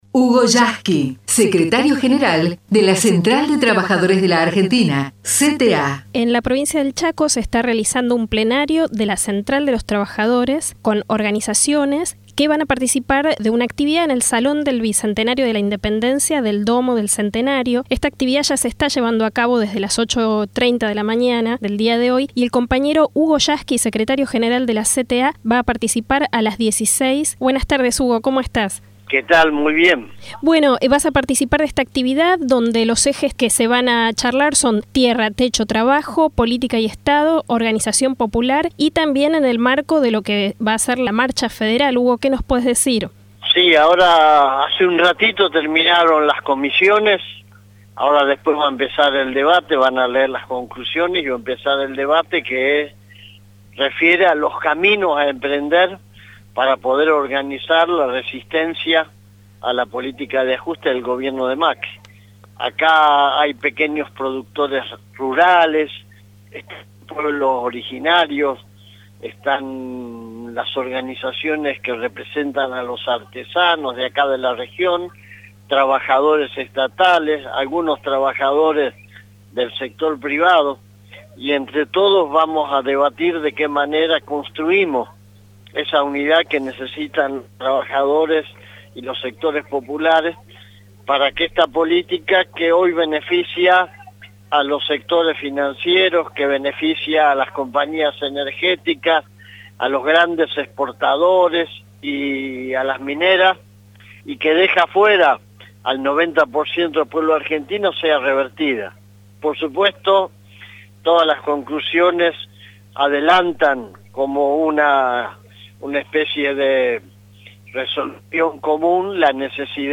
HUGO YASKY desde CHACO entrevistado en RADIO CENTRAL